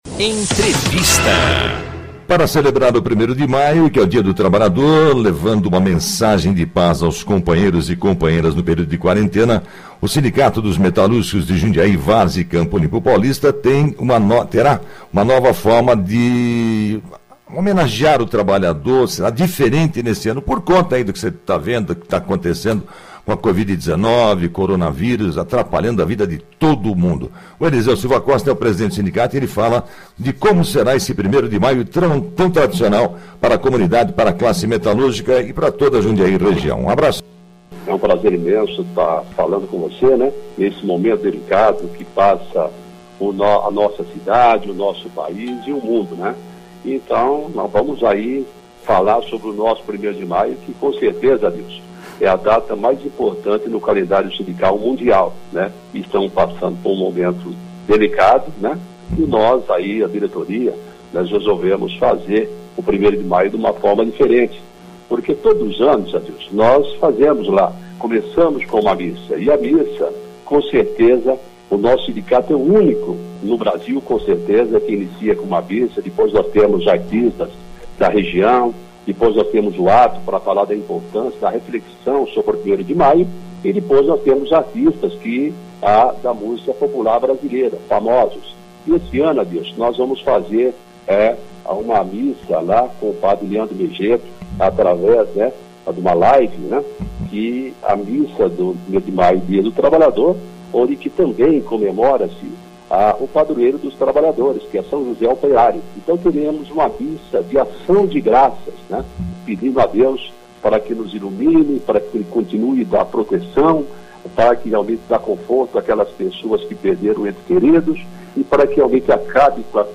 O presidente marcou presença na Radio Difusora Jundiaí (AM 810), acompanhe: